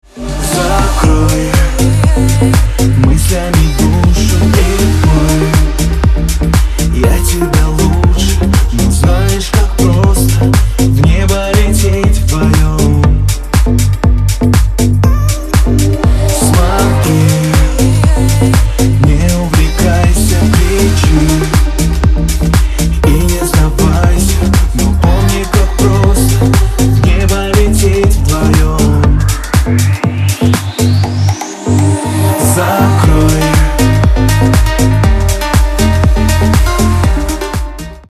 поп
мужской вокал
dance